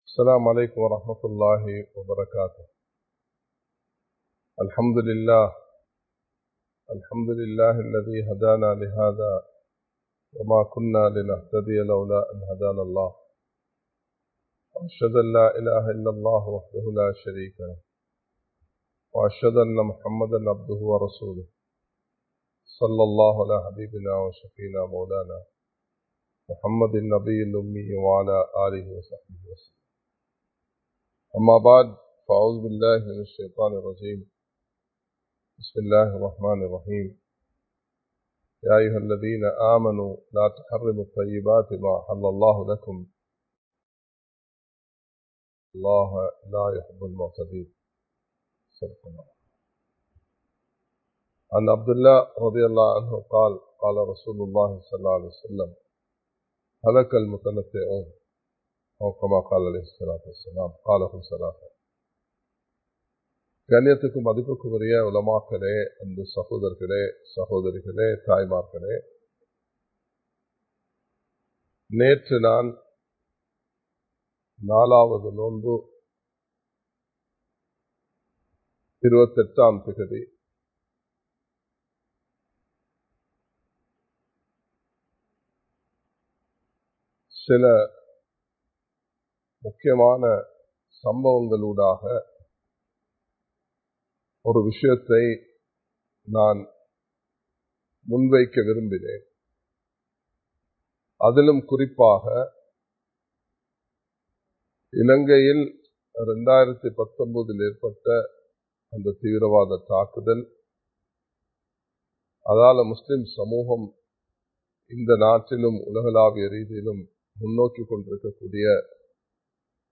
நிதானமாக நடந்து கொள்வோம் (பகுதி 2) | Audio Bayans | All Ceylon Muslim Youth Community | Addalaichenai
Live Stream